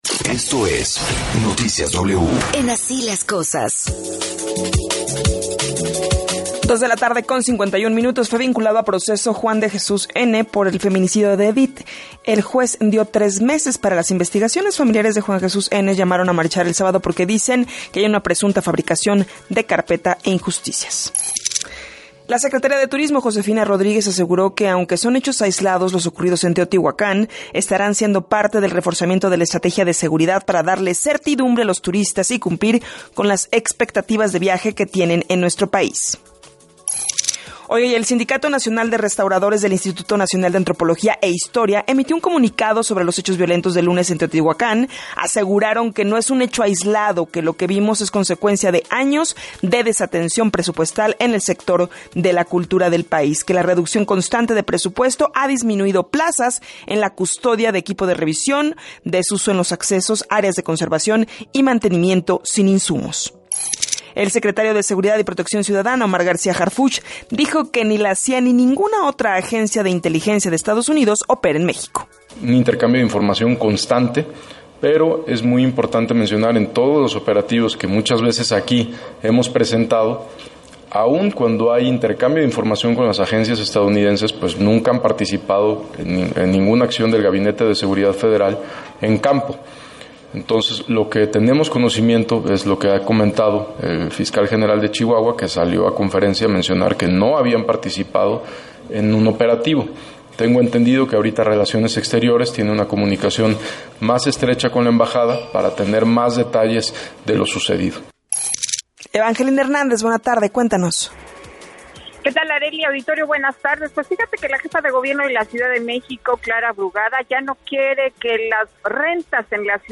Resumen informativo